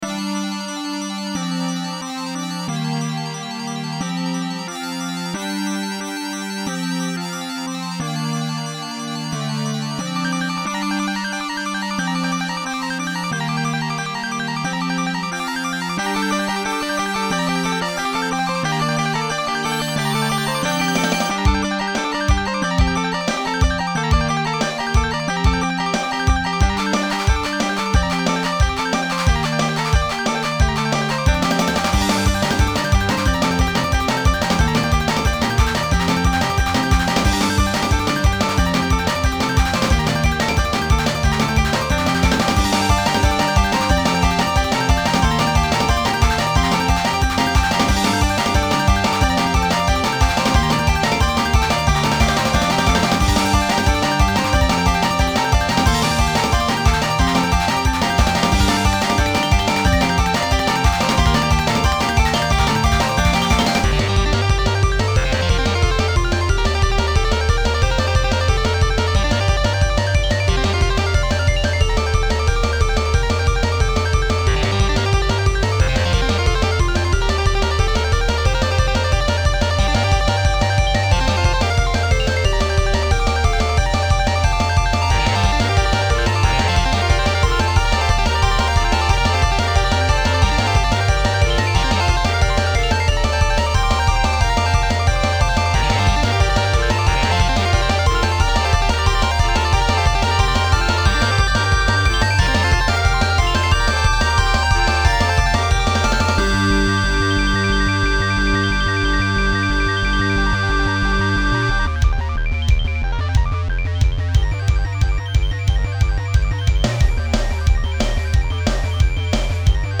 old 1990's techno hit